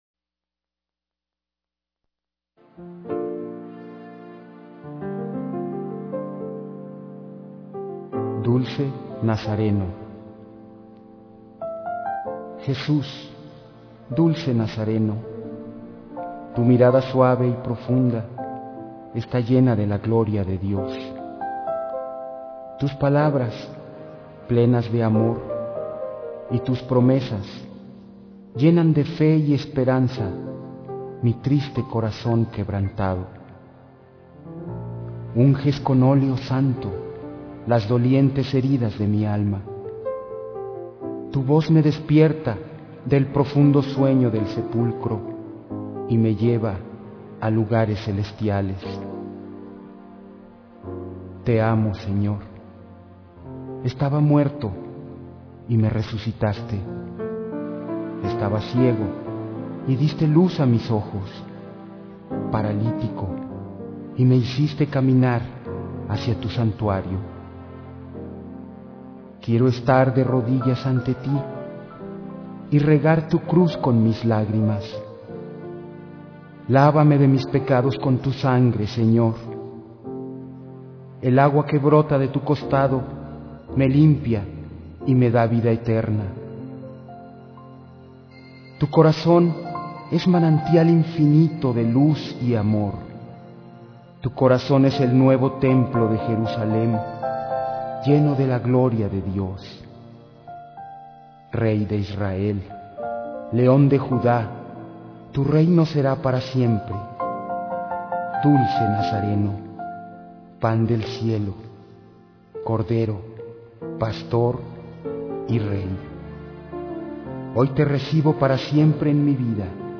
P O E M A S
C A L I D A D     M O N O